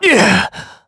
Clause_ice-Vox_Damage_kr_02_c.wav